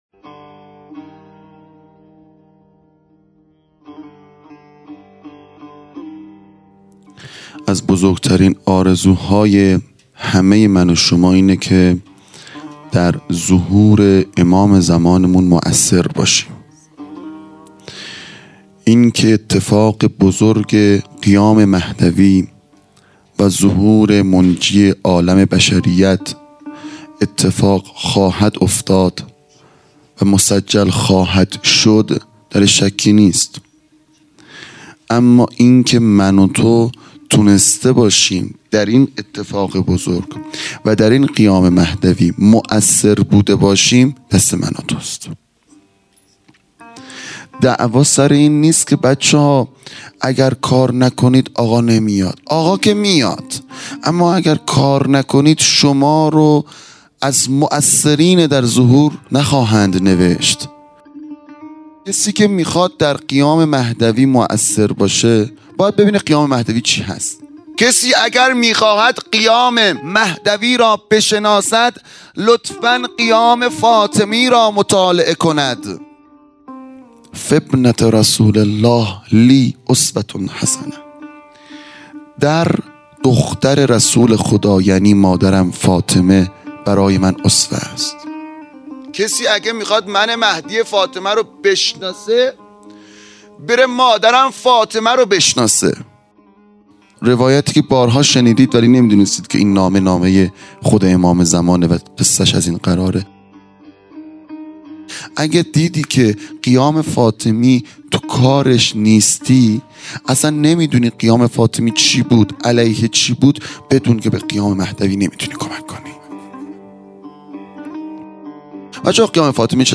عزاداری فاطمیه اول | شب اول ۲۸ دی ۱۳۹۷